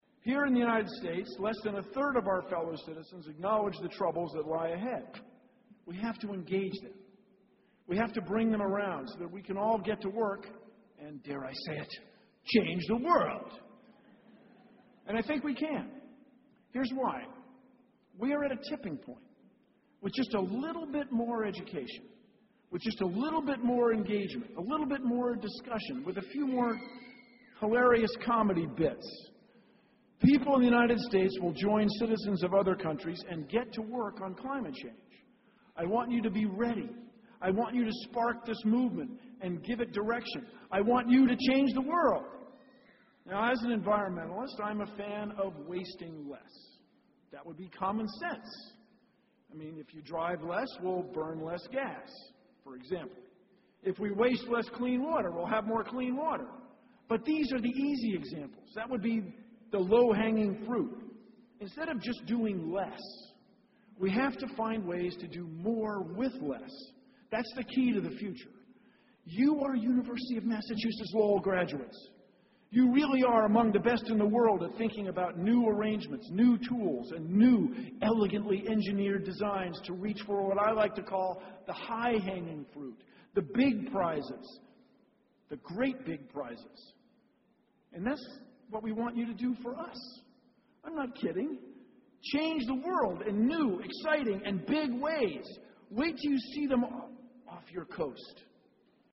公众人物毕业演讲 第164期:比尔·奈马萨诸塞大学2014(11) 听力文件下载—在线英语听力室